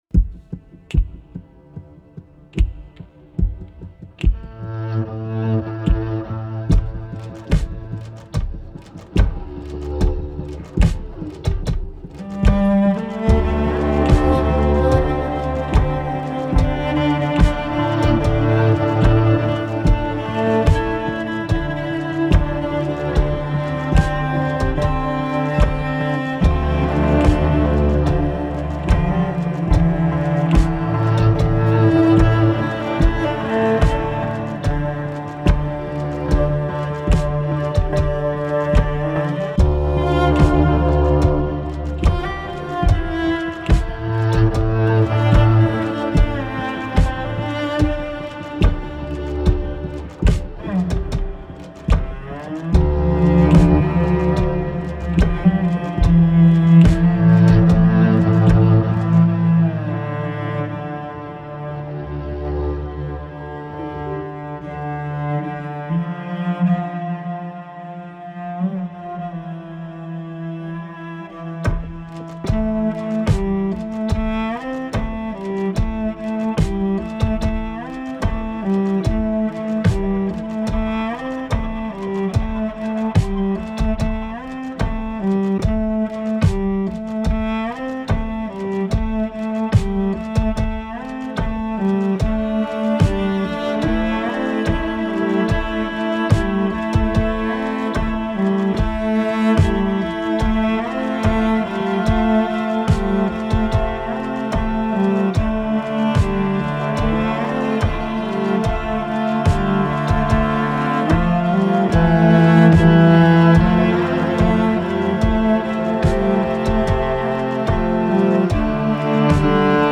Part 4 of my solo cello album.
Yes they are improvised pieces.